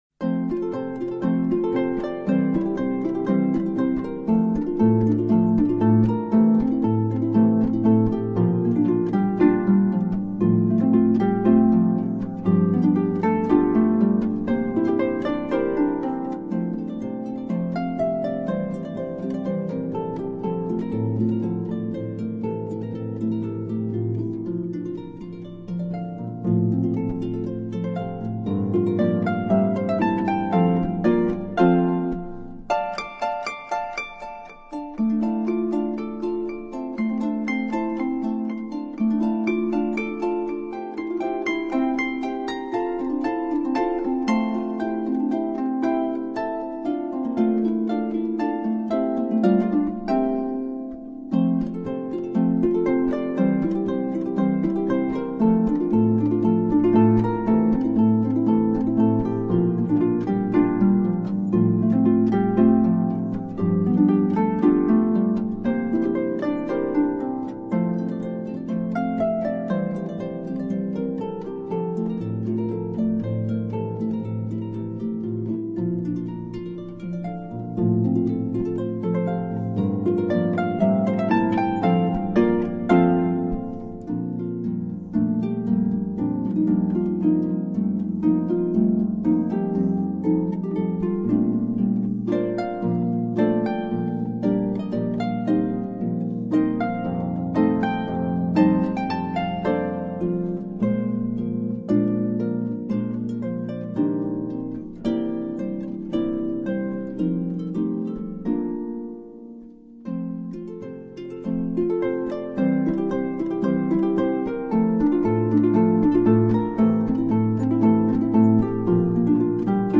Paso doble
Paso_doble_-2-.mp3